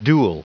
Prononciation du mot duel en anglais (fichier audio)
Prononciation du mot : duel